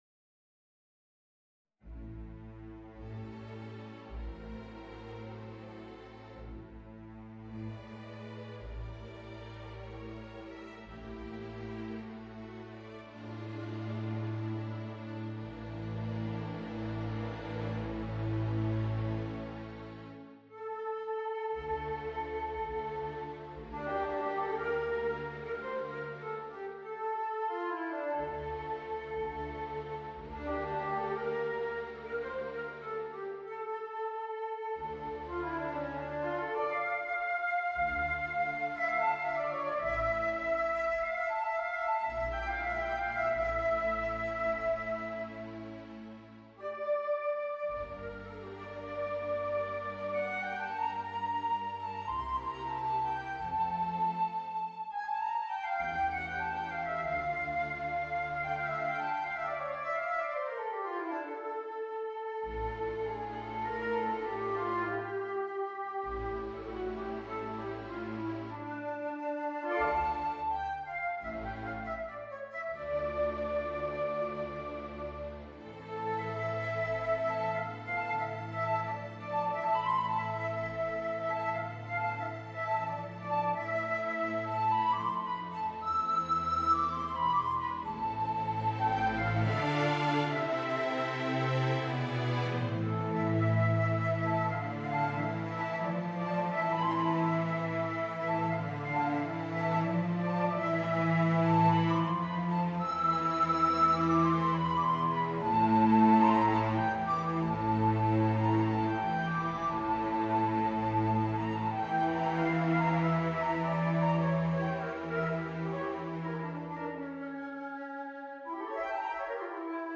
Voicing: Violin and String Orchestra